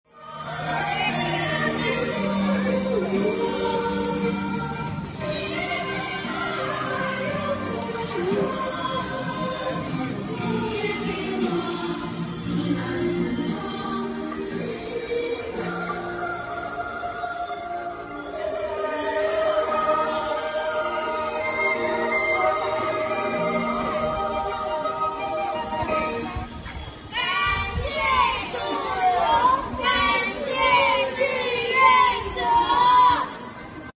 小区居民自发在阳台传唱《歌唱祖国》